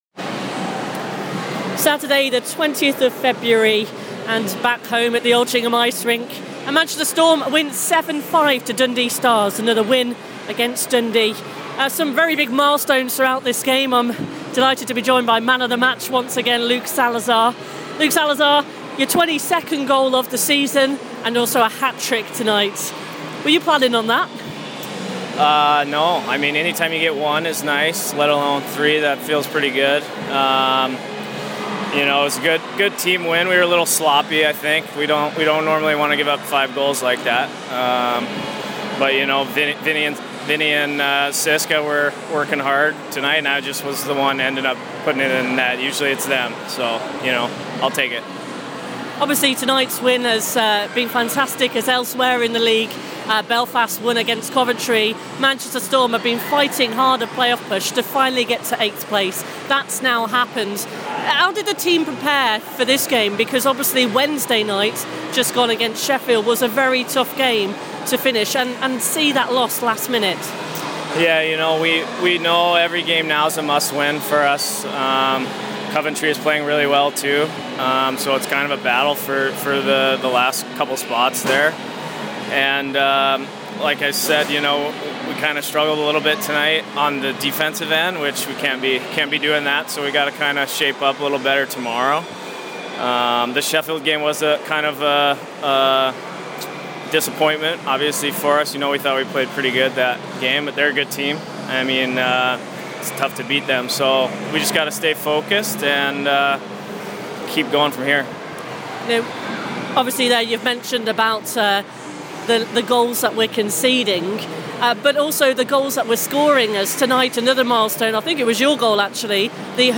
Post match, he speaks